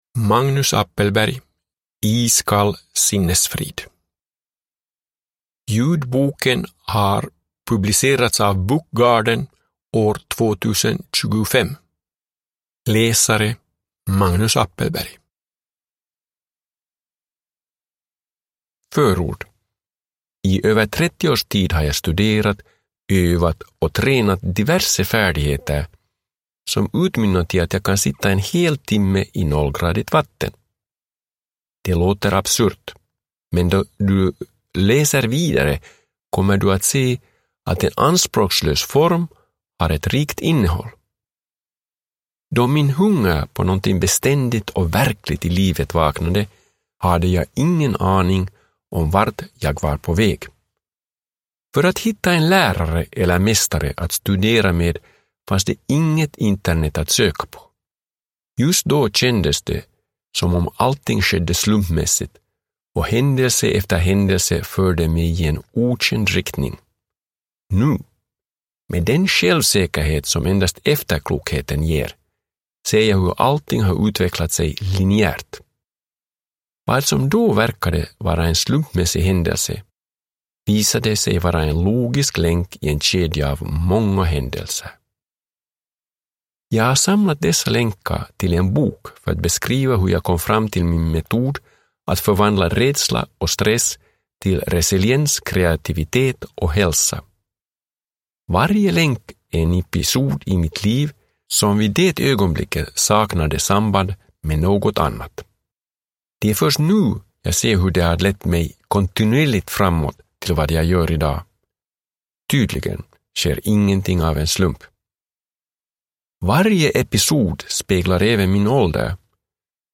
Iskall sinnesfrid – Ljudbok